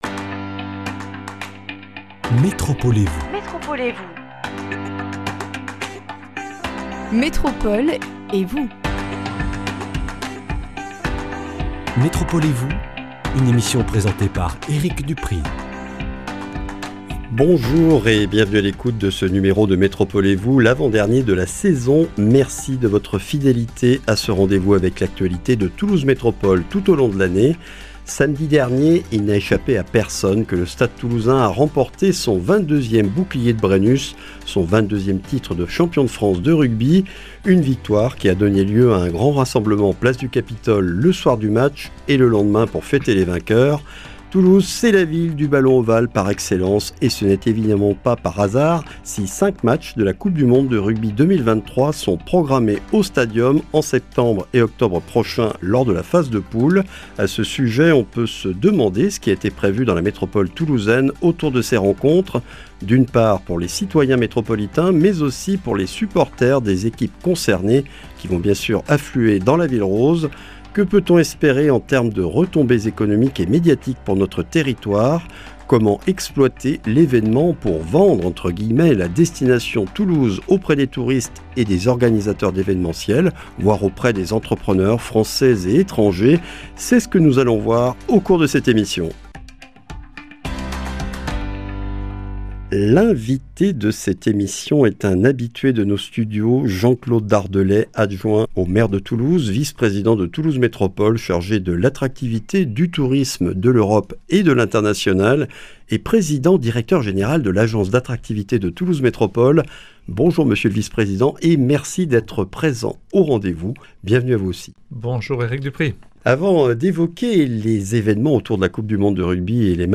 Cinq matchs de la coupe du monde de rugby 2023 auront lieu à Toulouse. Quels événements sont organisés autour de ces rencontres et quelles retombées peut-on espérer pour la Métropole ? Une émission avec Jean-Claude Dardelet, adjoint au maire de Toulouse, vice-président de Toulouse Métropole chargé de l’attractivité, du tourisme, de l’Europe et de l’international, PDG de l’Agence d’attractivité.